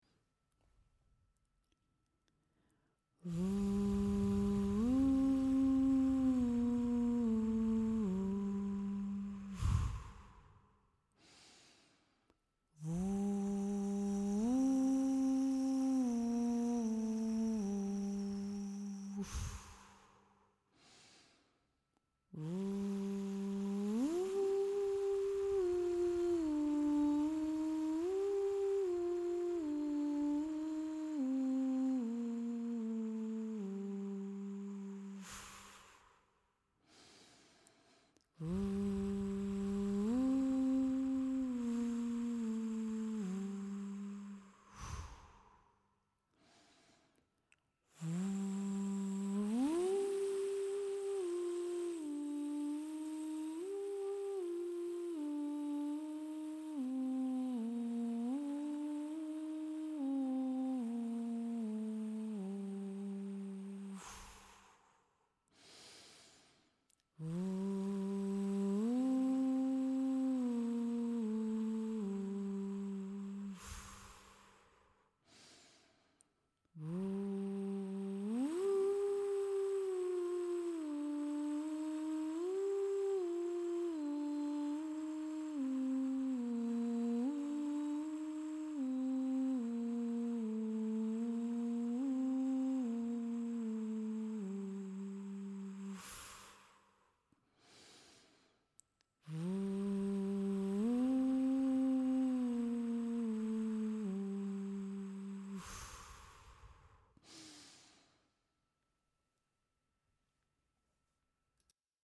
En meditativ andningsövning där du jobbar med att få ett jämt luftflöde oavsett hur lång frasen är.
o eller u, men sjung bara på w. Andas in genom näsan, ta djupa andetag ända ner i bäckenbotten och tänk mycket flöde på det ljudande w -ljudet.
Fraserna skall vara omväxlande kort och långa men alltid lätta med mycket luftflöde.Tänk jämn och långsam puls under hela övningen.
* = ljudlig utandning där man tömmer ut all luft i puls